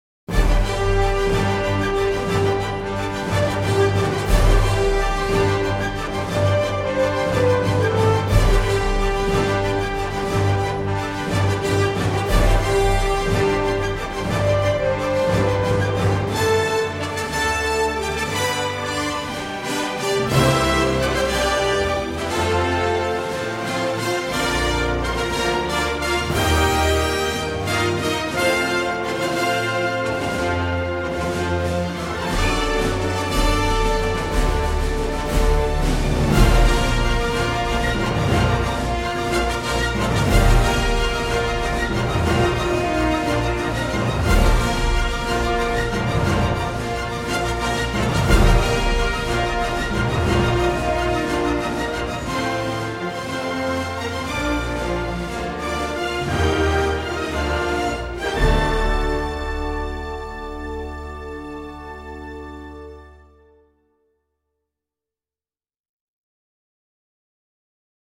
其他音乐
该BGM音质清晰、流畅，源文件无声音水印干扰